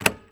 AudioClip_Click-Medium.wav